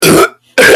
Two Demonic Burps Bouton sonore